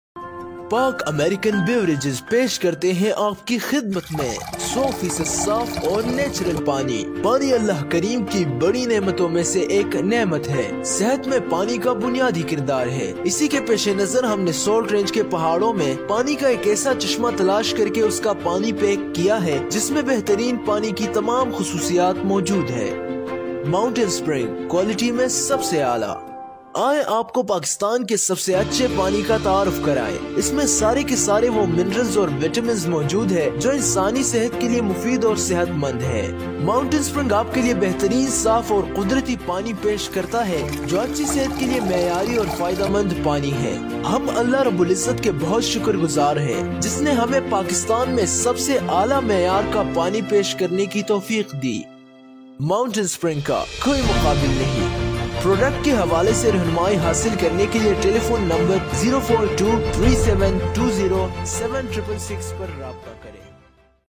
动画配音